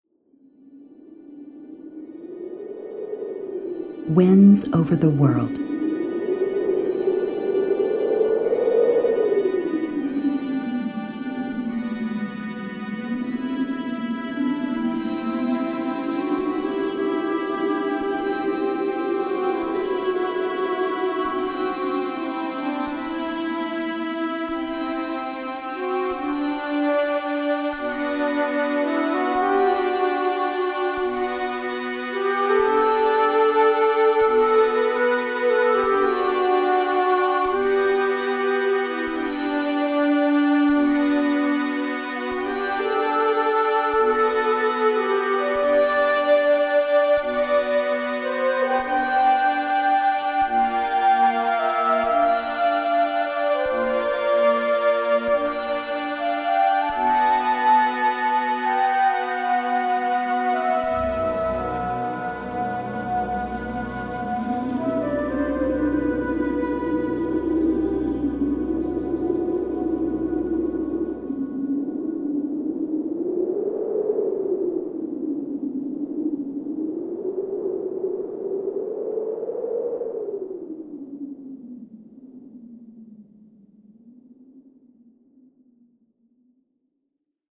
Relaxace, Meditace, Relaxační a Meditační hudba
Verbální vedení: Neverbální